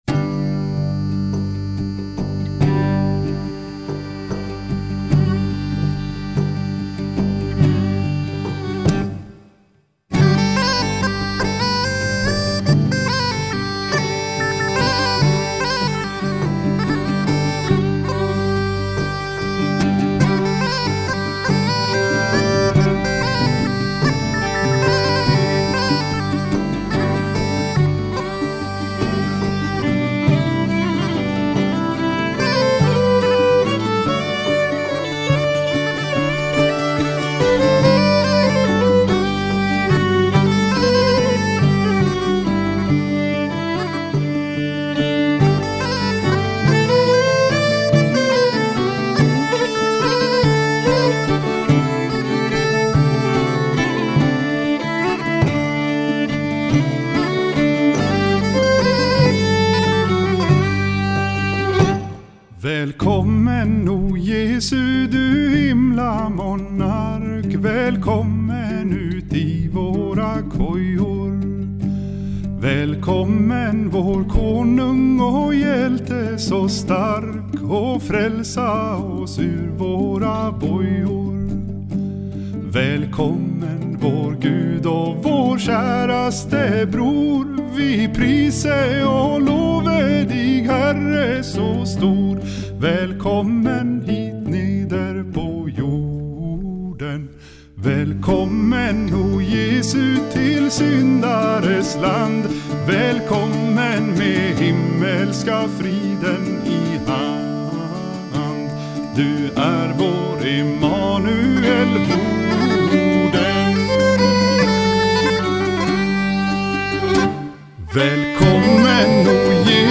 聞こえてくるのは、スウェーデンのダラルナ州エルヴダーレン地方のクリスマスの歌です。